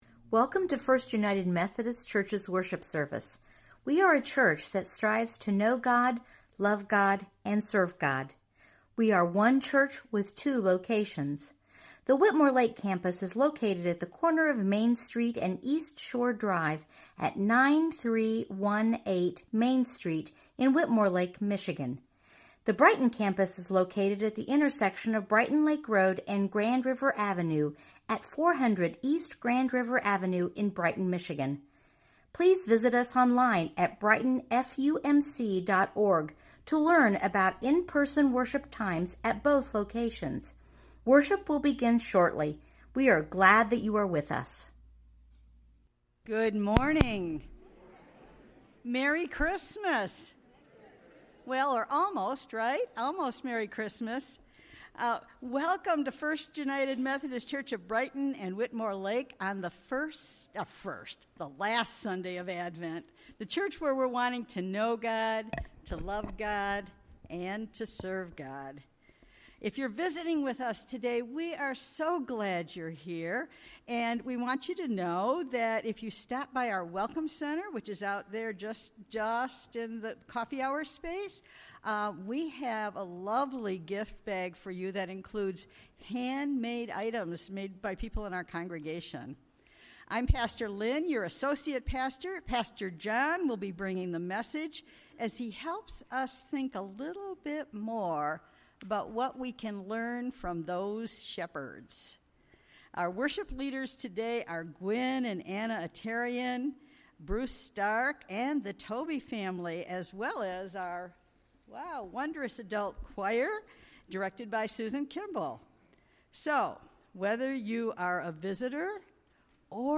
Sermons recorded live at Brighton First United Methodist Church in Brighton, Michigan.